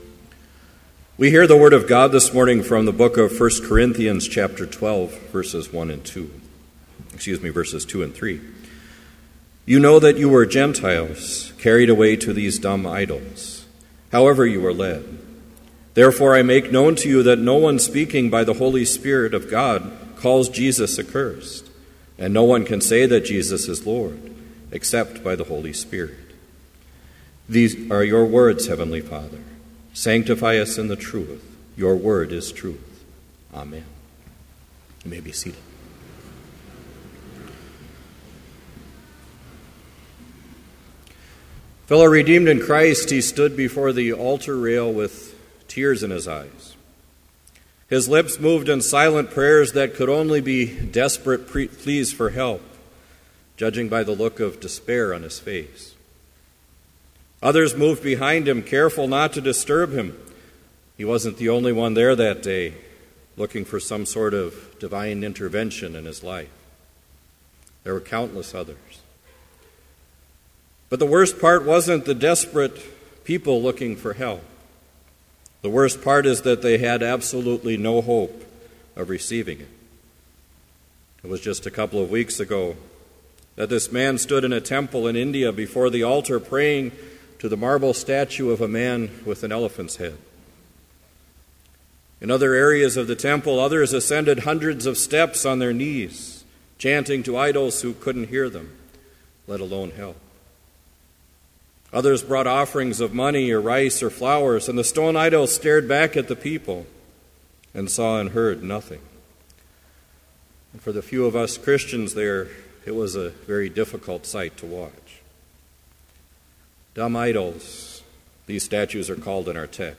Sermon Only
This Chapel Service was held in Trinity Chapel at Bethany Lutheran College on Thursday, February 20, 2014, at 10 a.m. Page and hymn numbers are from the Evangelical Lutheran Hymnary.